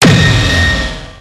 railgun-turret-gunshot-1.ogg